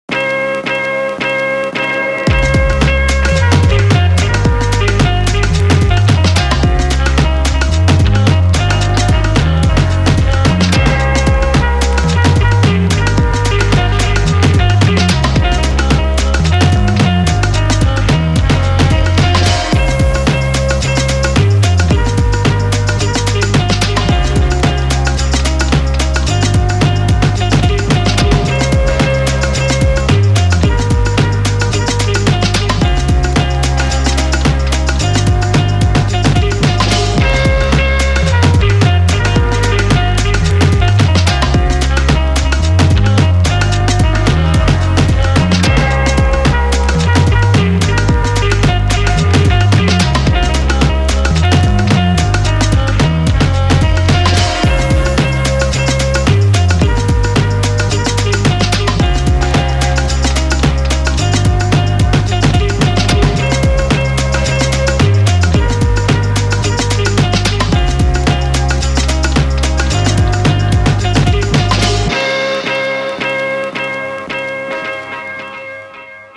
(Pop, Trap, DnB, reklamní spot,...)